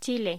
Locución: Chile